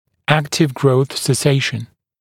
[‘æktɪv grəuθ se’seɪʃn][‘эктив гроус сэ’сэйшн]окончание активного роста